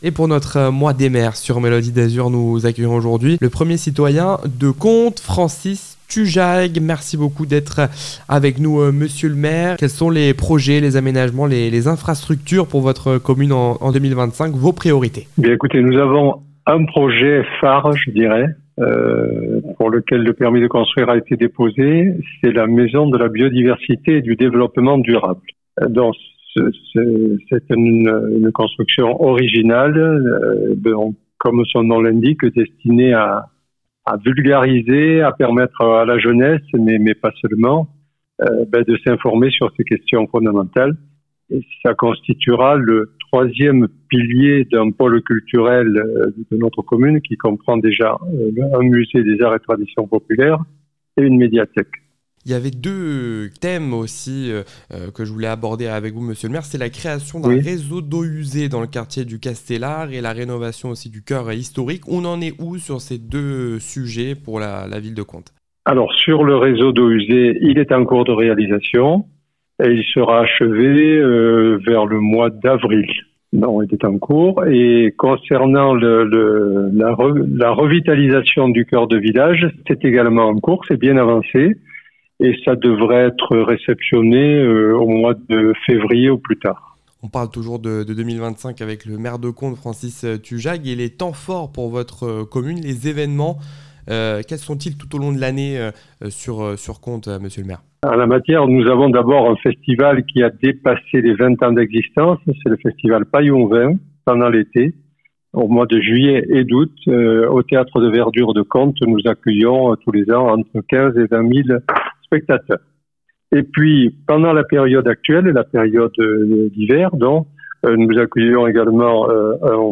Interview des Maires - Episode 7 : Contes avec Francis Tujague